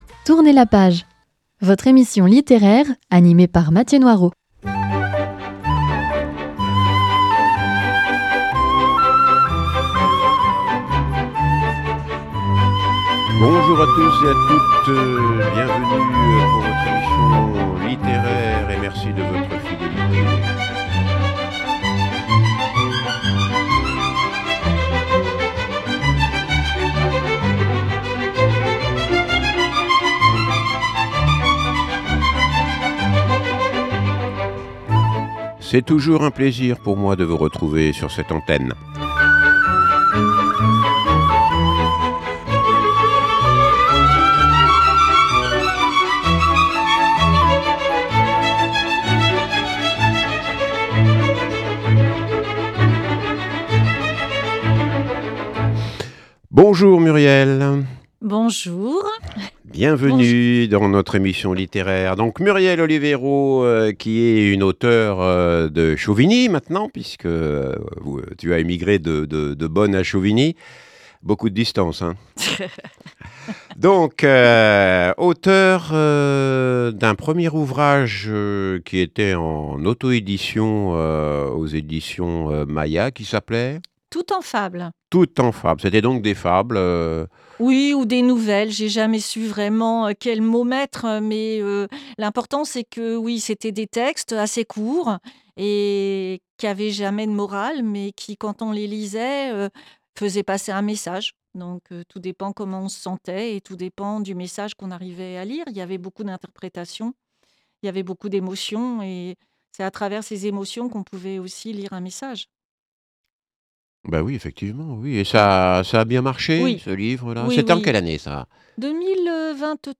Émission littéraire avec un·e invité·e : auteur ou autrice qui nous parle de son métier, de ses ouvrages ou de son dernier livre édité.